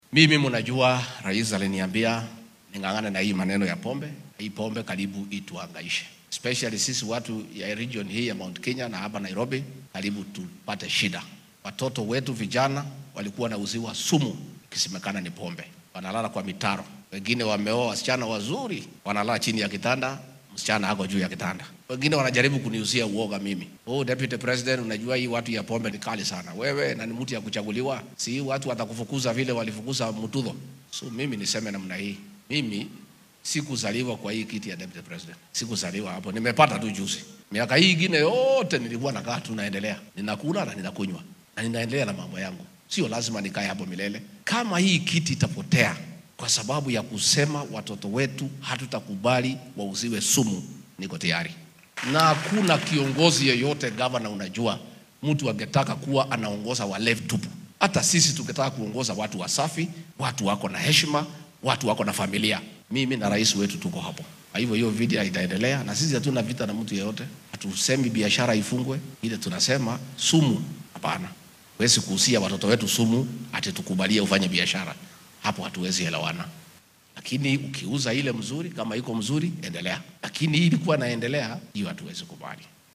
Arrimahan ayuu saaka ka sheegay munaasabad kaniiseed oo uu uga qayb galay deegaanka Roysambu ee ismaamulka Nairobi.